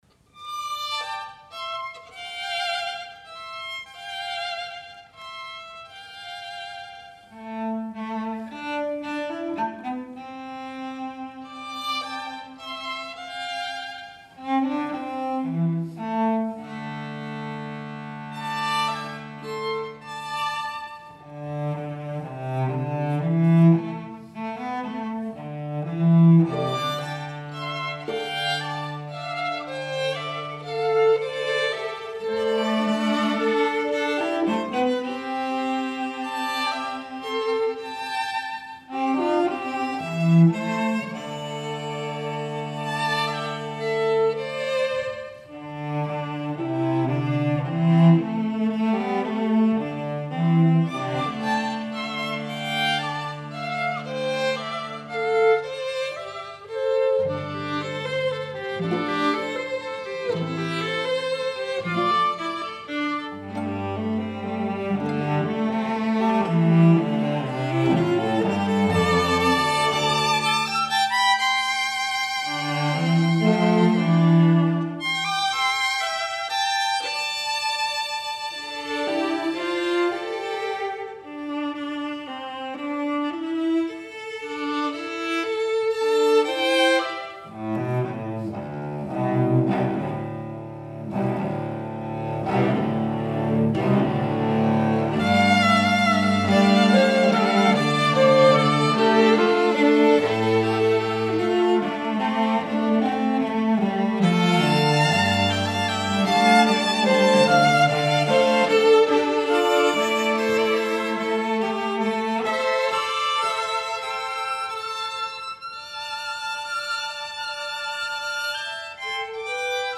for String Quartet (2014)